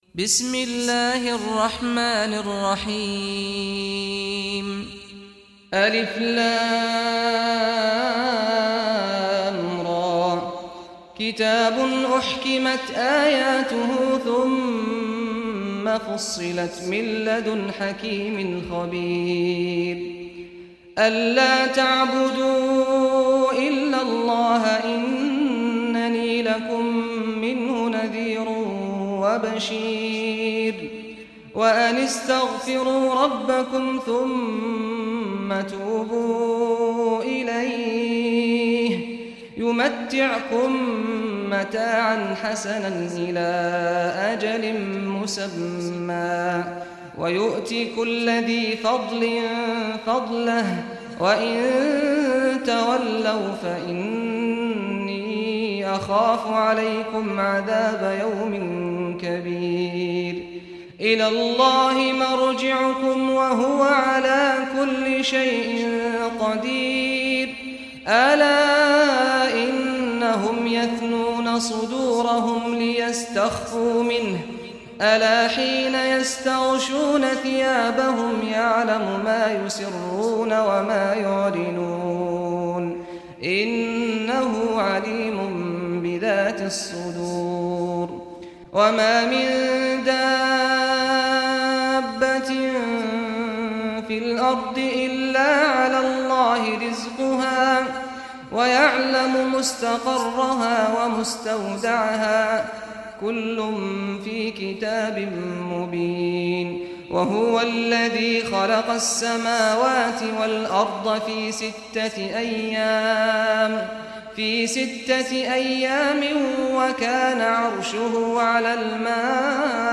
Surah Hud Recitation by Sheikh Saad Al Ghamdi
Surah Hud, listen or play online mp3 tilawat / recitation in Arabic in the beautiful voice of Imam Sheikh Saad al Ghamdi.
11-surah-hud.mp3